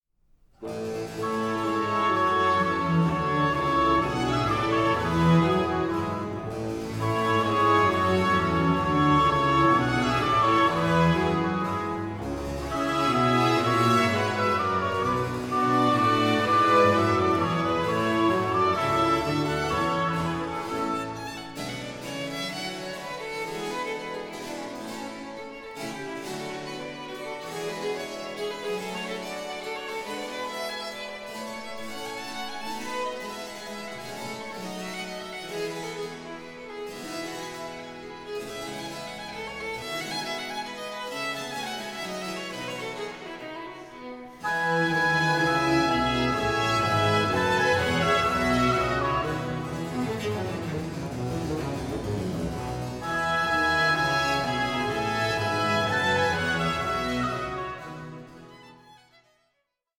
INSTRUMENTALISTS PUT CENTRE STAGE
Baroque ensembles